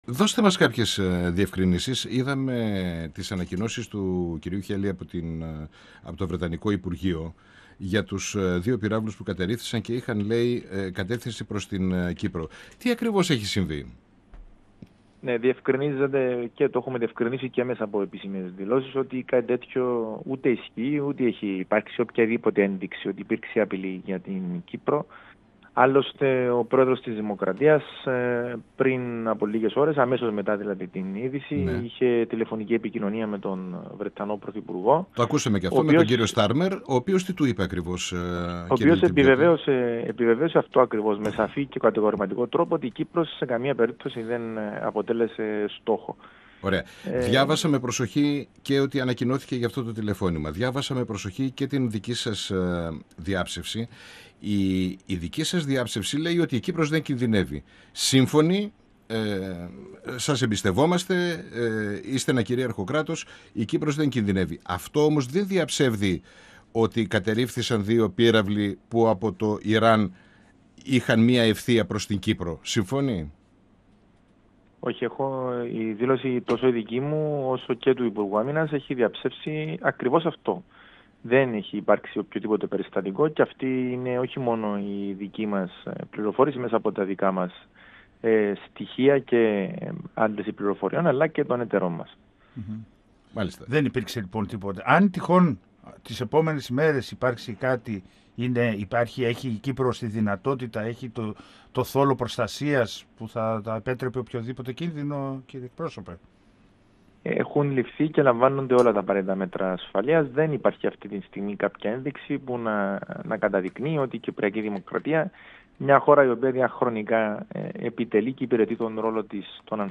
Ο Κύπριος κυβερνητικός εκπρόσωπος, Κωνσταντίνος Λετυμπιωτης στον 102FM της ΕΡΤ3 | 01.03.2026